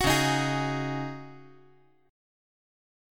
C#sus2sus4 Chord
Listen to C#sus2sus4 strummed